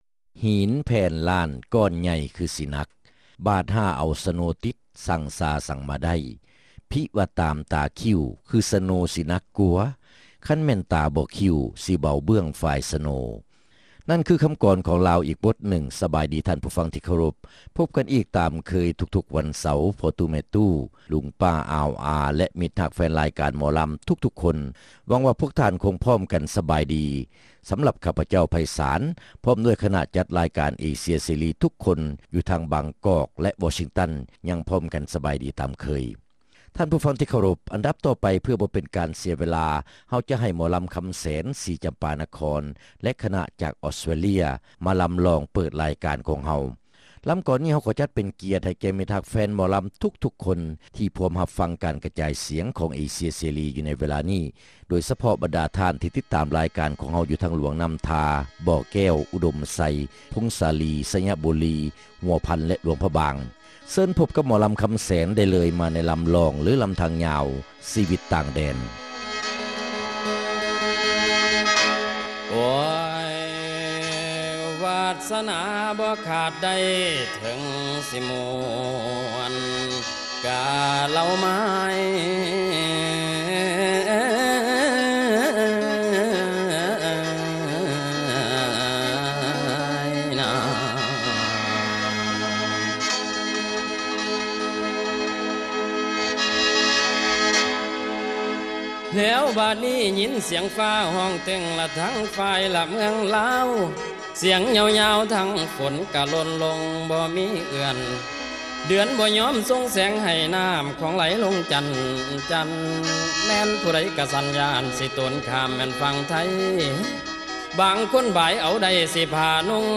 ຣາຍການໜໍລຳ ປະຈຳສັປະດາ ວັນທີ 9 ເດືອນ ທັນວາ ປີ 2005